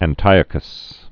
(ăn-tīə-kəs)